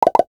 NOTIFICATION_Pop_03_mono.wav